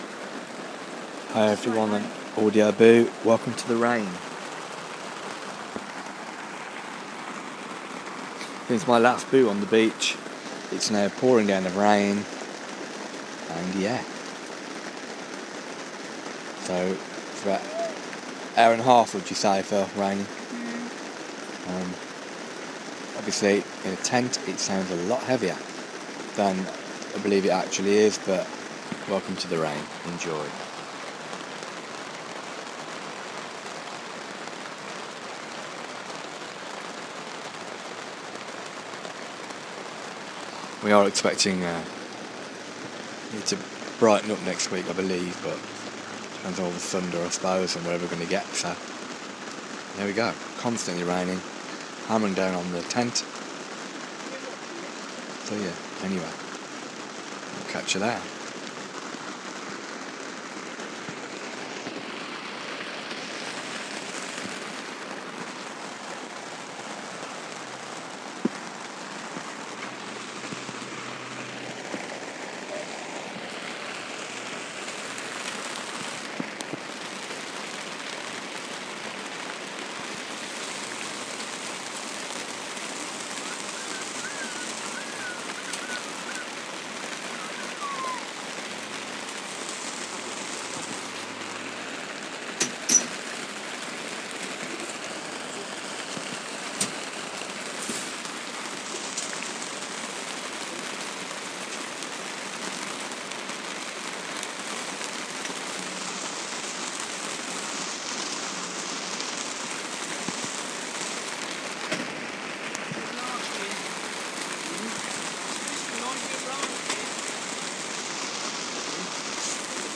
rain anyone?